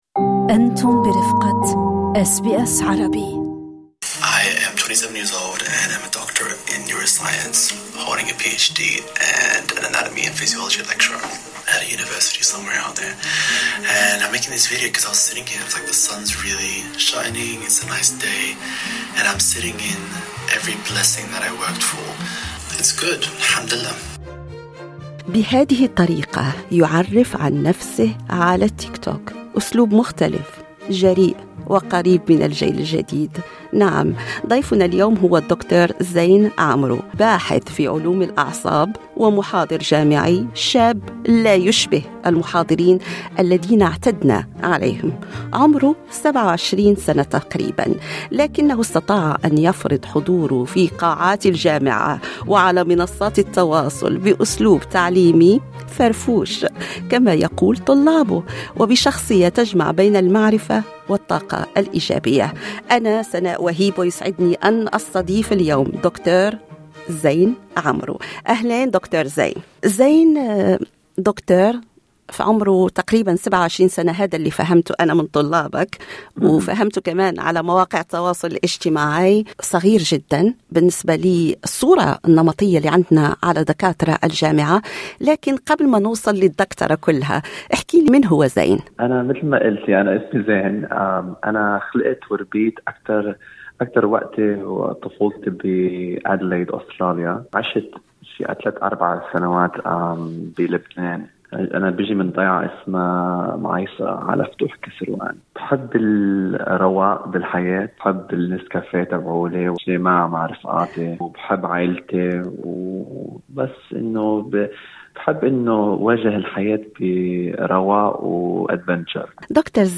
في مقابلة